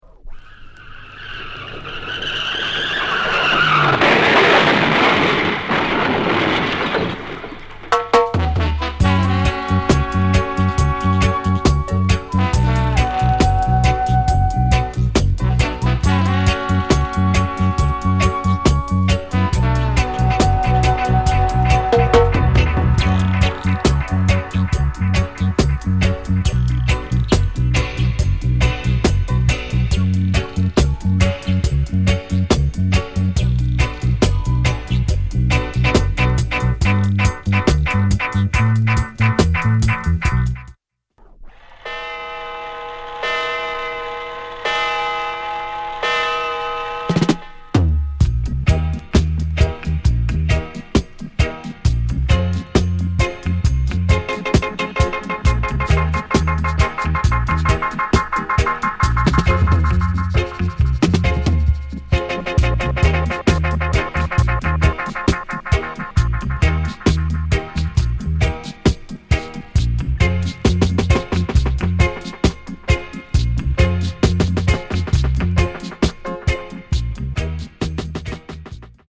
RARE DUB EP